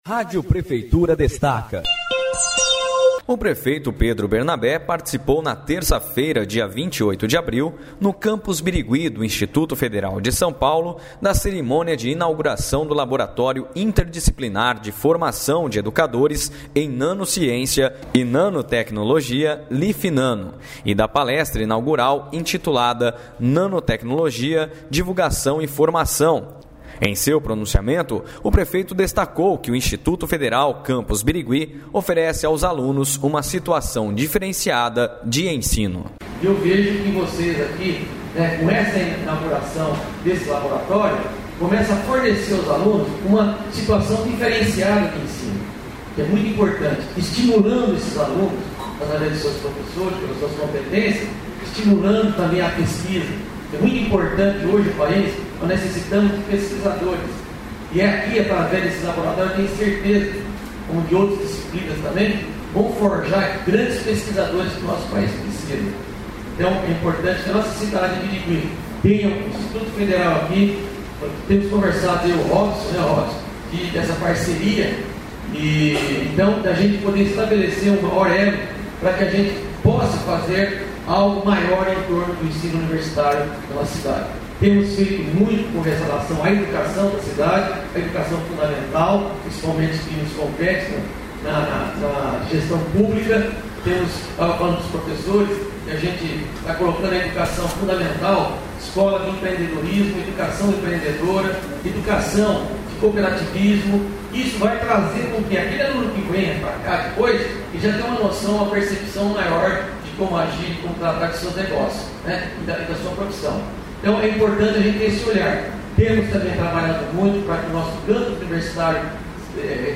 A Rádio Prefeitura esteve no evento e traz trecho do pronunciamento do prefeito Pedro Bernabé.